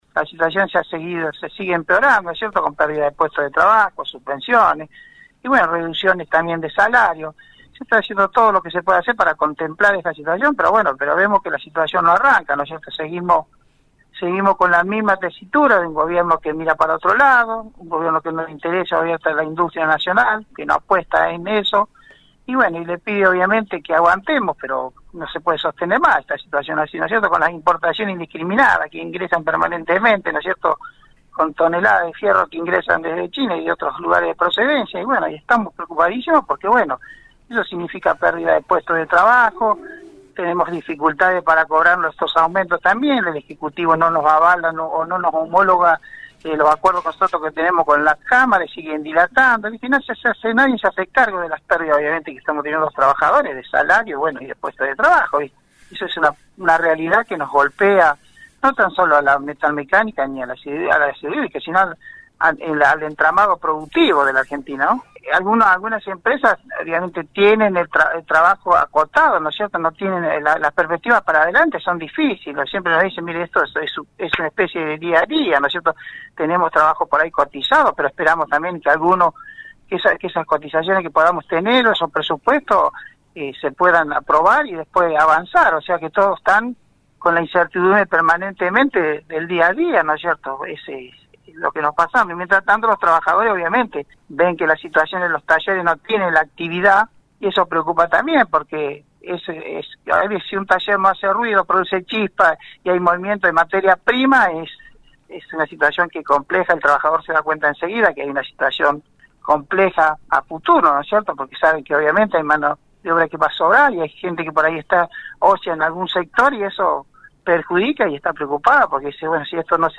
EN RADIO UNIVERSO 93 1